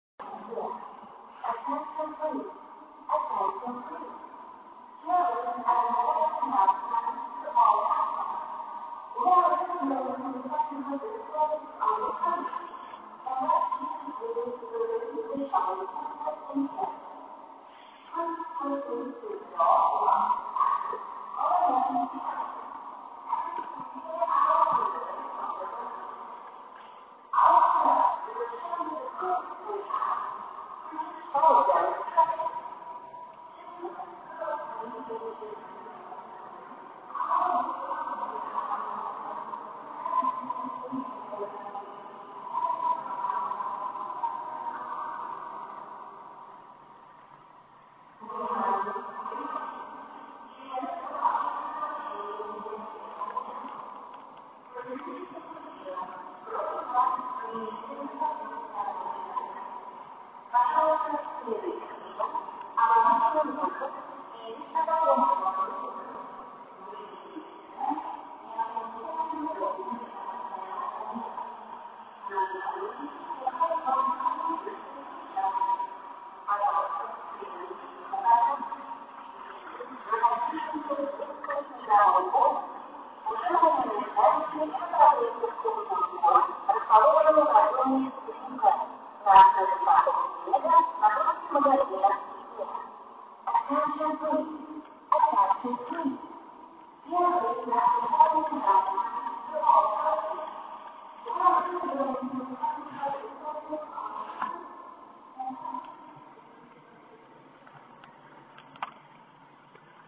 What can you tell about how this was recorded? Evacuation of Ikea store (recorded in Moscow)711.65 kB50kB1